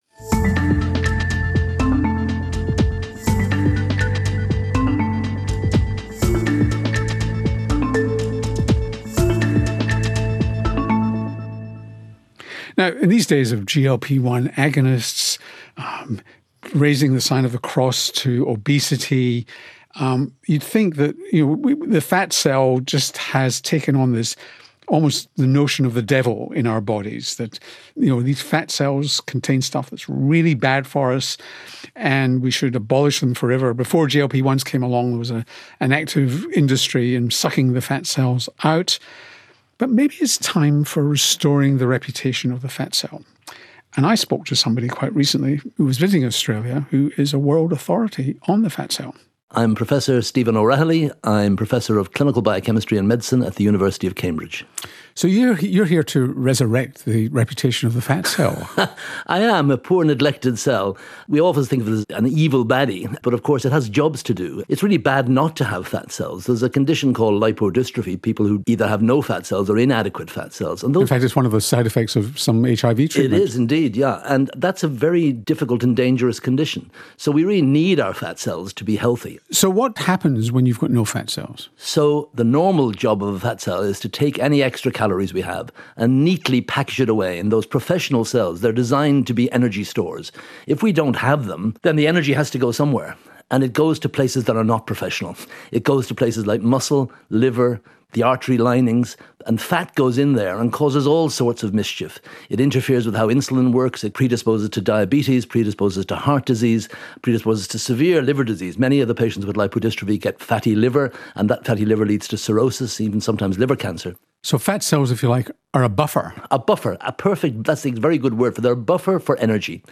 Professor Sir Stephen O’Rahilly delivered the Charles Perkins Centre capstone the Nicholas Catchlove Lecture 2025.